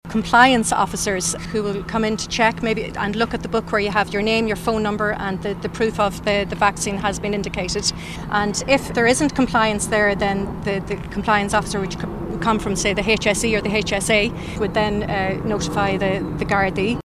Tourism Minister Catherine Martin says the new measures will be strictly monitored……………..